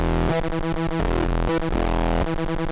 Descarga de Sonidos mp3 Gratis: robot 12.